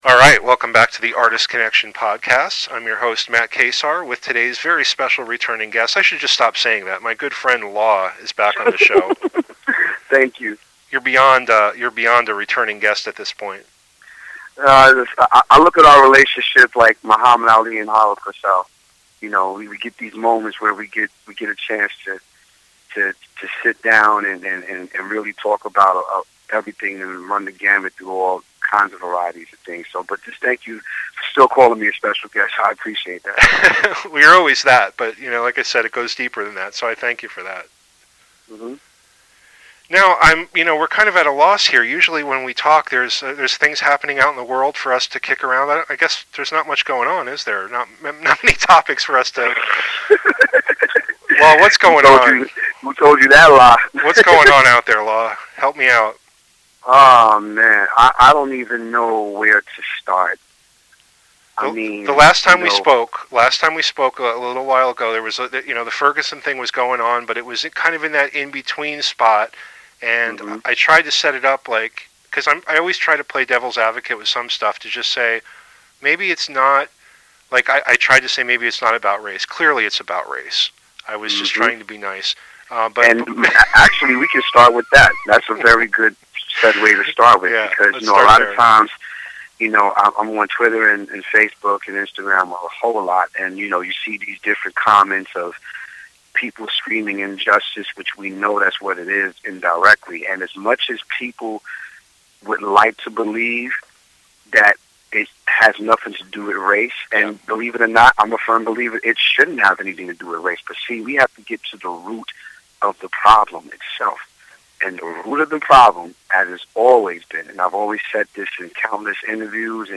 Two live tracks are featured here as well.